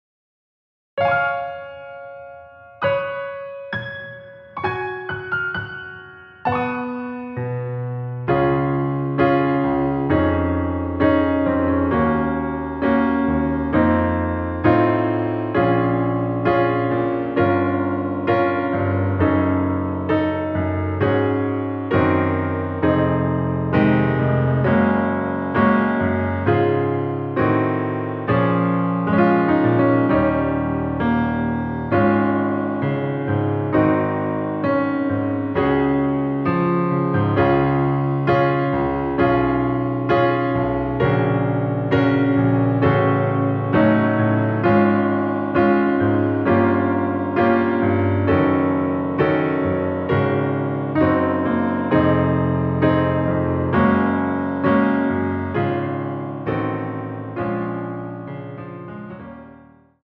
대부분의 여성분이 부르실수 있는 키로 제작 하였습니다.
C#
앞부분30초, 뒷부분30초씩 편집해서 올려 드리고 있습니다.
중간에 음이 끈어지고 다시 나오는 이유는